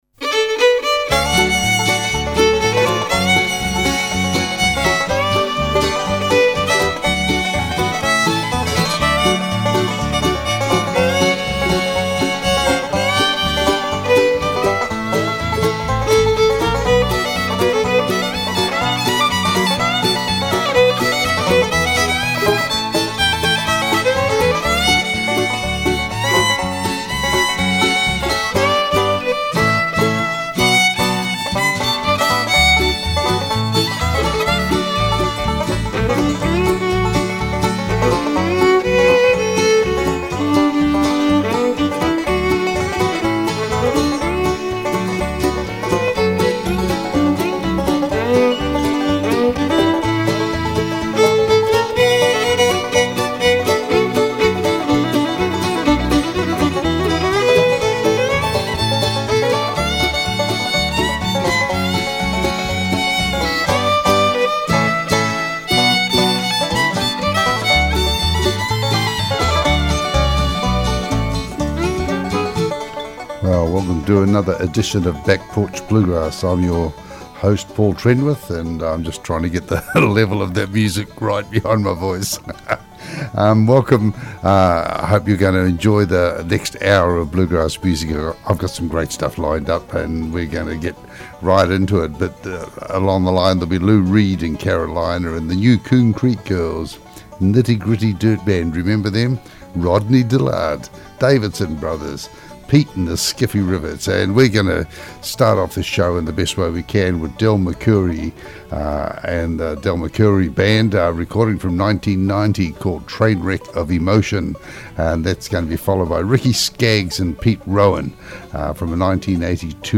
Back Porch Bluegrass Show - 14 February 2017